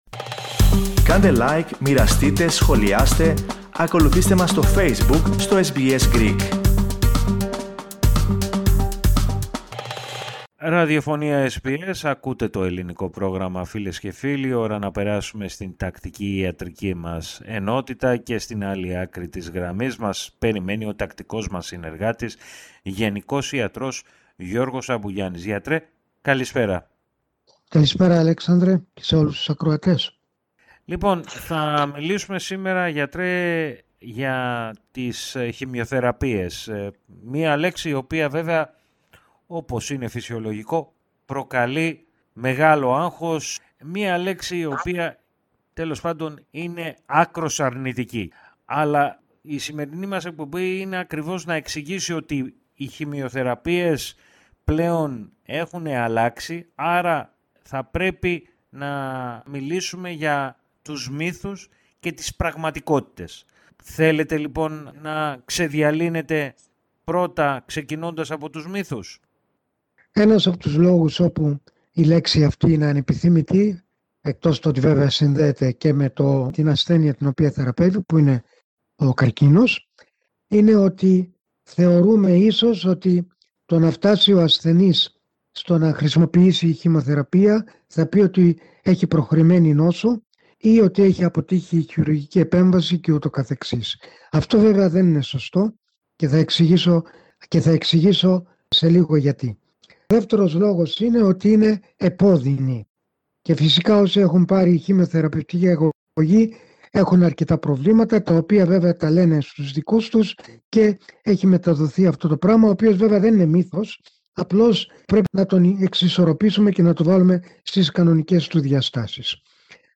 Ακούστε ολόκληρη τη συνέντευξη πατώντας το σύμβολο στο μέσο της κεντρικής φωτογραφίας.